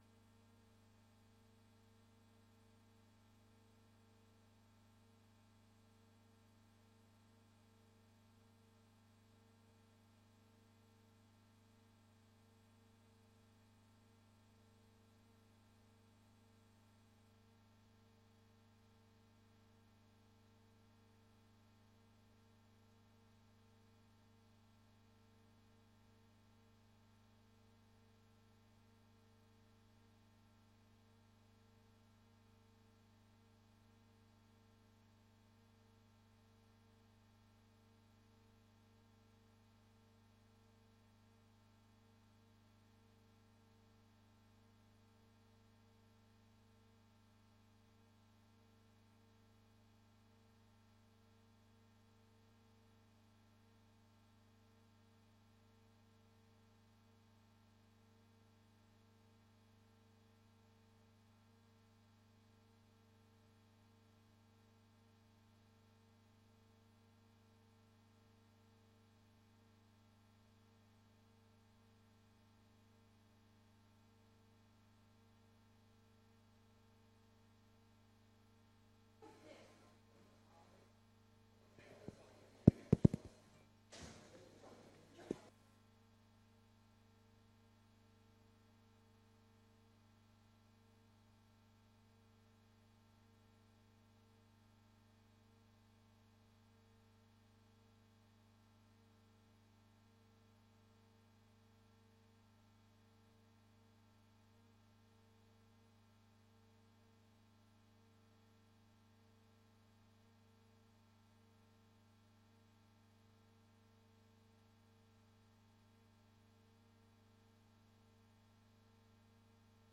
Informerende raadsvergadering over Windbeleid Hof van Twente.
Locatie: Raadzaal